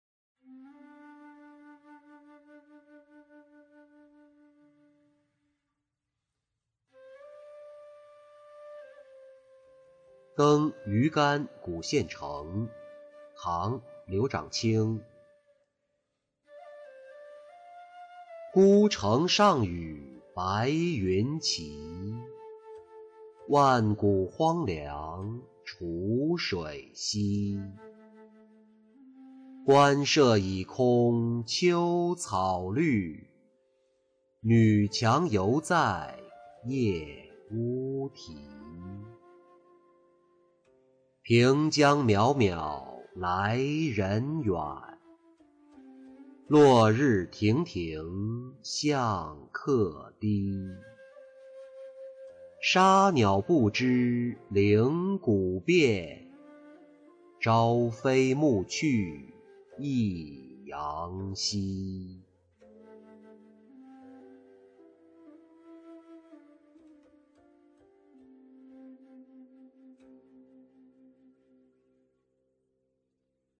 登馀干古县城-音频朗读